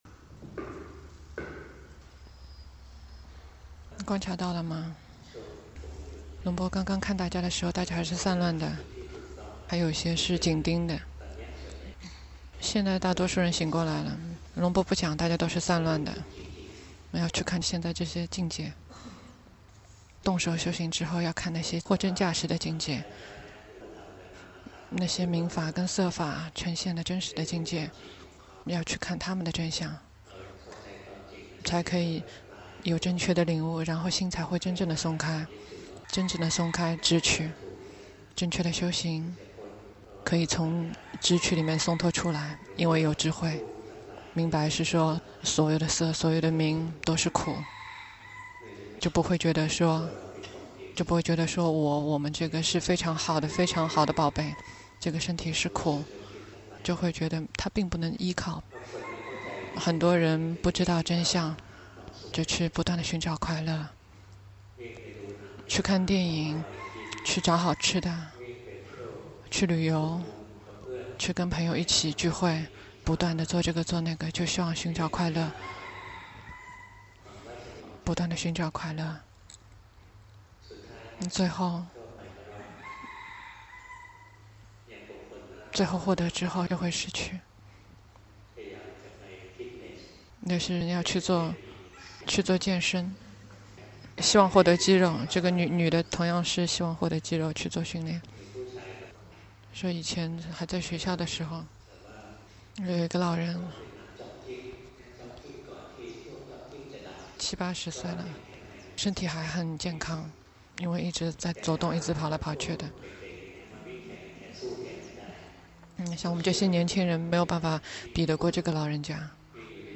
長篇法談｜修行的快樂和固定形式觀心
泰國解脫園寺 同聲翻譯